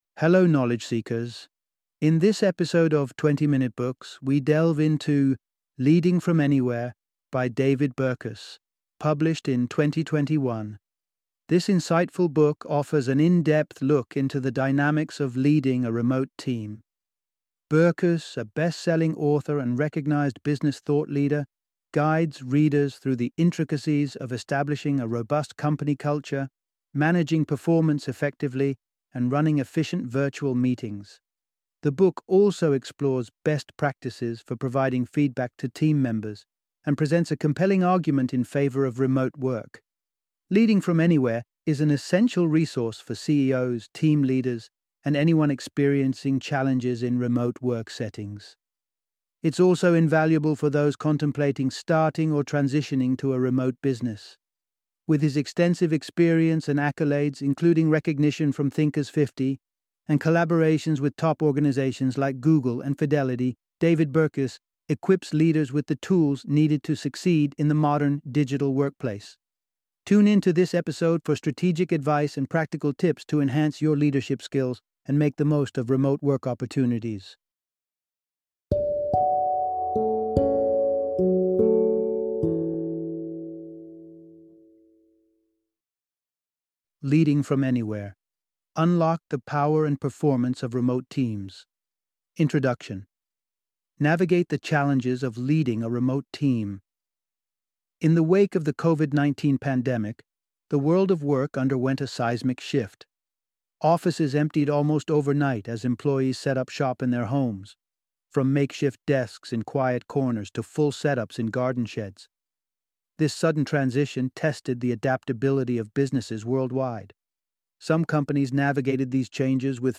Leading from Anywhere - Audiobook Summary